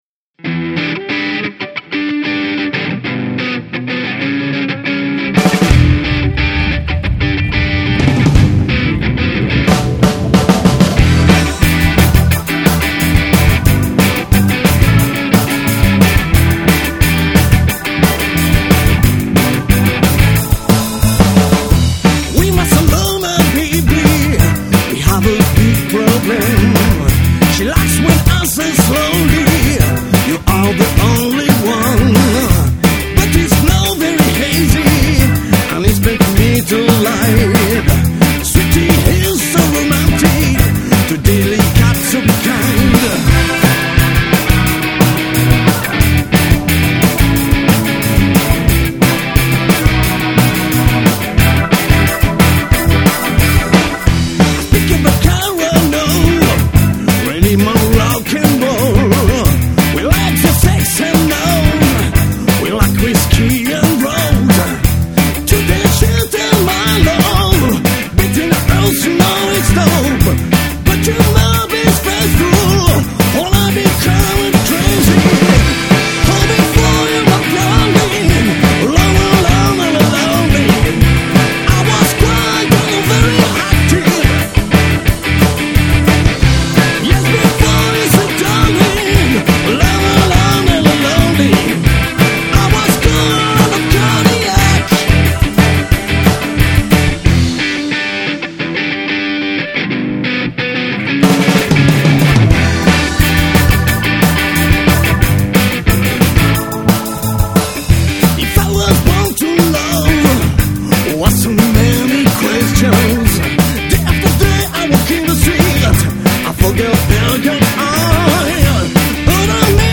Enregistrement studio
Guitare
Basse
Batterie
Chant / clavier